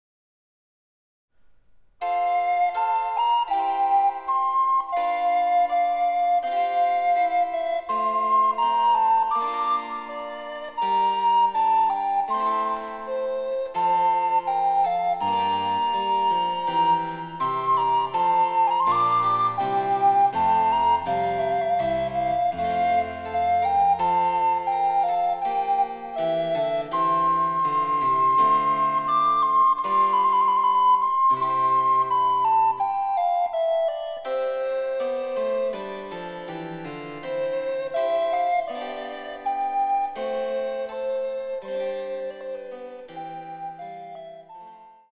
４つの楽章から成り、緩・急・緩・急という構成になっています。
第１楽章はアダージョ（ゆっくりと）４分の４拍子。
■リコーダーによる演奏